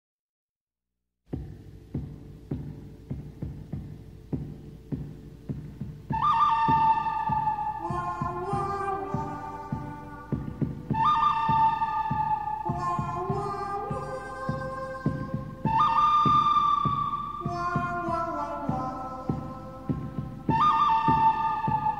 • Качество: высокое
Музыка Дикого запада у всех на слуху